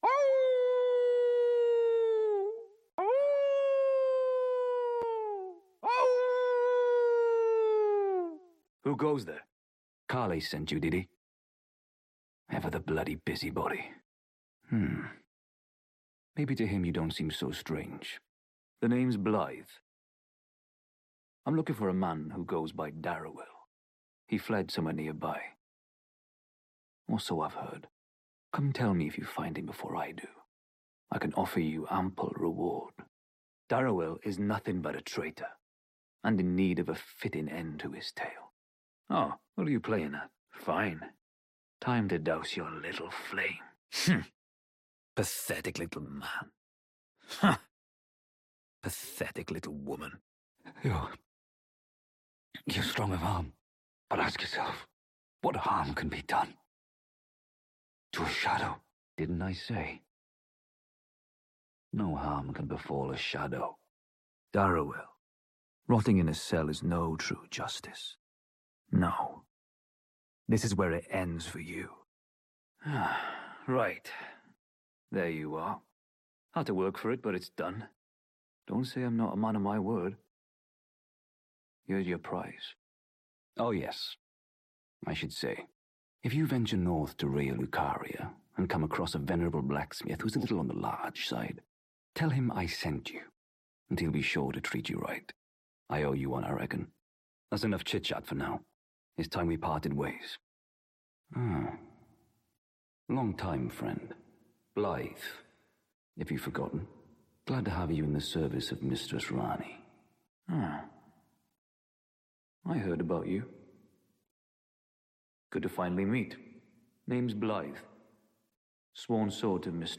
Elden Ring - Blaidd Dialogue Compilation [F7oIkhP_fxU].mp3